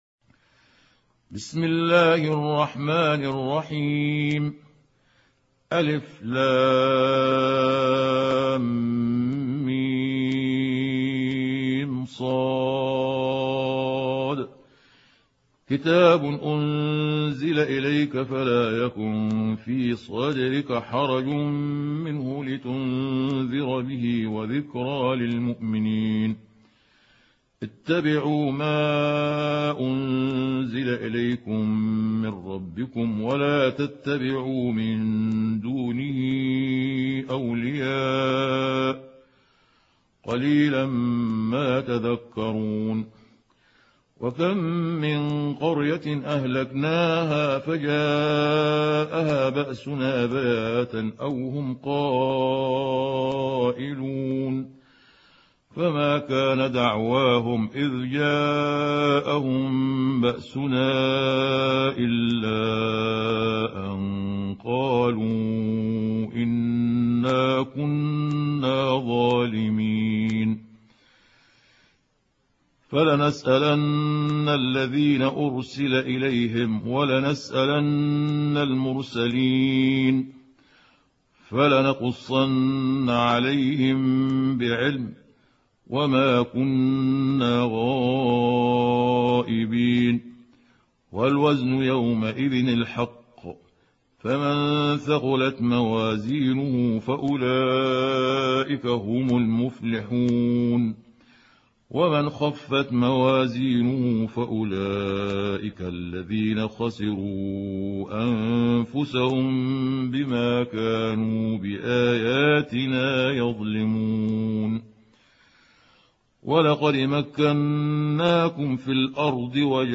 سورة الأعراف | القارئ محمود عبد الحكم
سورة الأعراف مكية عدد الآيات:206 مكتوبة بخط عثماني كبير واضح من المصحف الشريف مع التفسير والتلاوة بصوت مشاهير القراء من موقع القرآن الكريم إسلام أون لاين